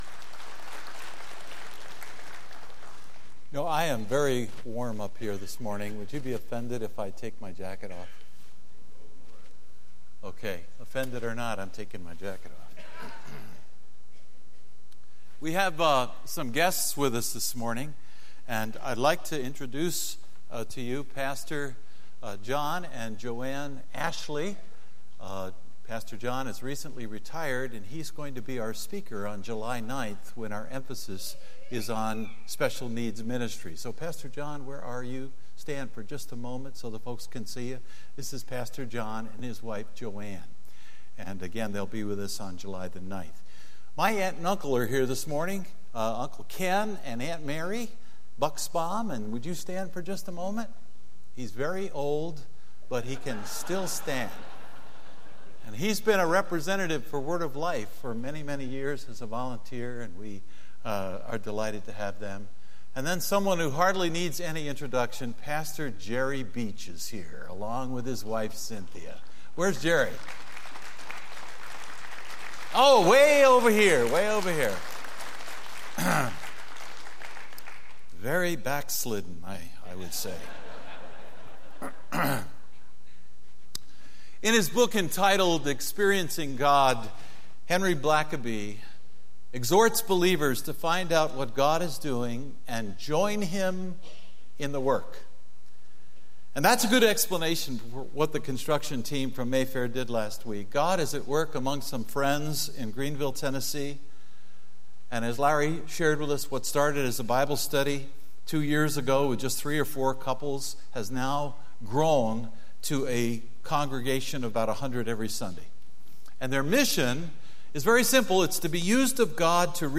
Sermons Archive - Mayfair Bible Church